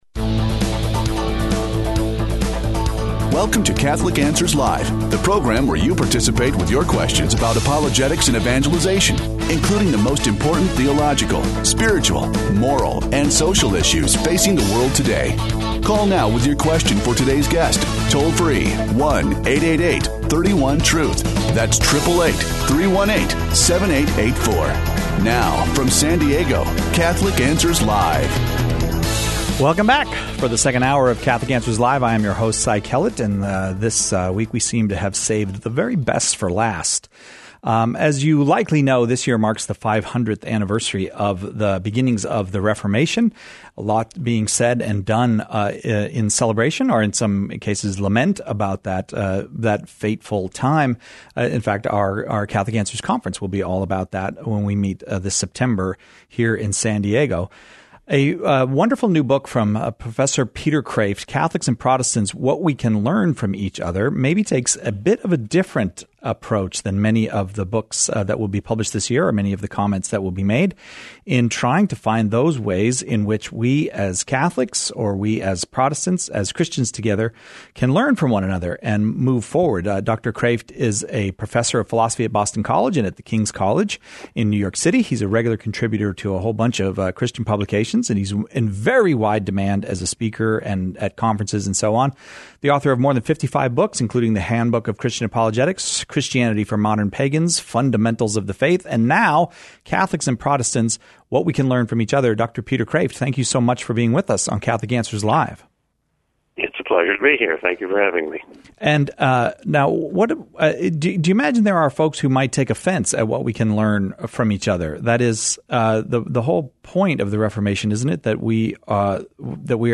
Renowned philosopher and apologist Dr. Peter Kreeft joins us for an hour of conversation and questions about what Catholics and Protestants can learn from each...